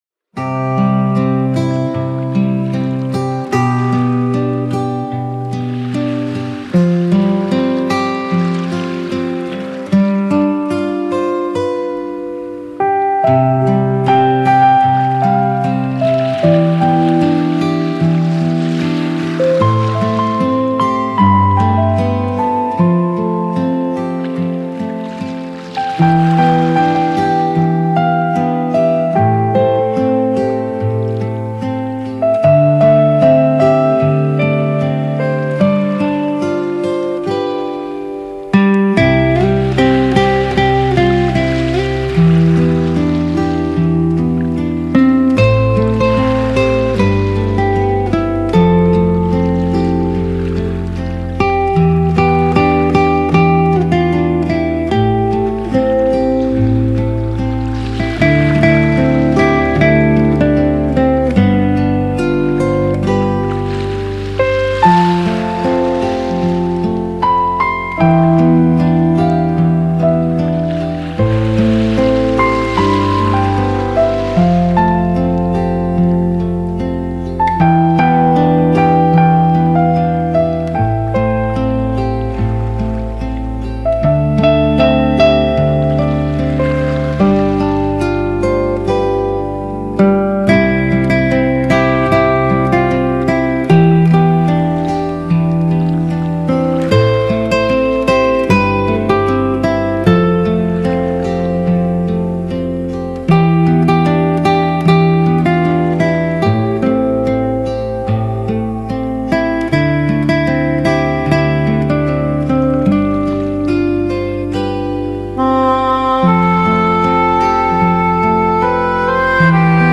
Колыбельные под звуки